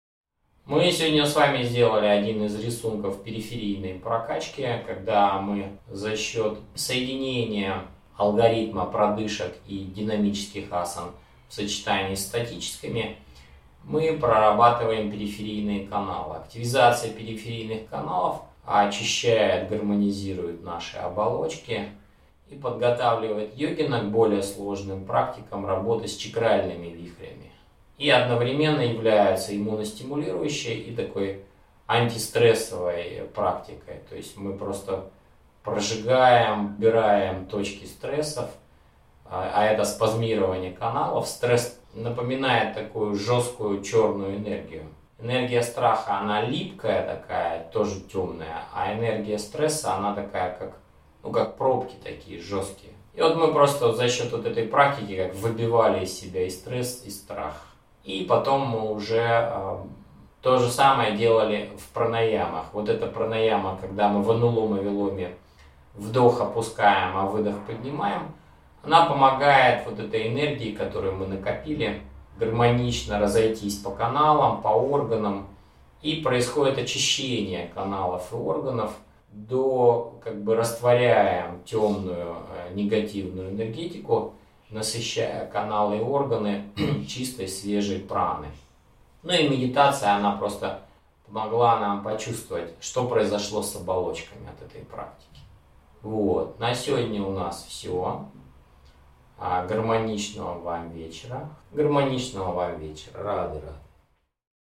Открытый урок, Экстрасенсорика